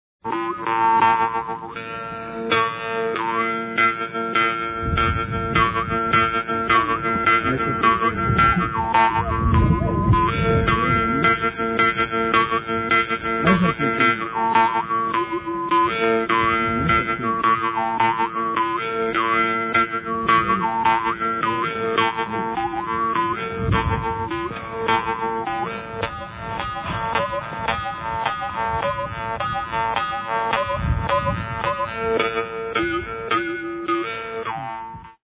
Instrumental - Jaw Harp - 1:39